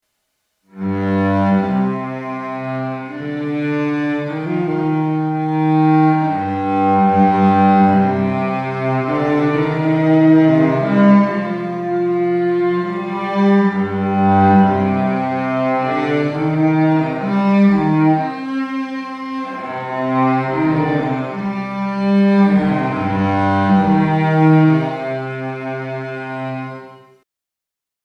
Ich habe zu jedem Effekt ein kurzes Sample jeweils mit Gitarre und Cello aufgenommen und geloopt und bin dann verschiedene Einstellungen durchgegangen.
Kopfhörer-Nutzer etwas aufpassen: Ab und zu übersteuert es leicht, weil ich es mit einigen Effekten vielleicht etwas übertrieben hab, da kann das Maw aber wenig dafür.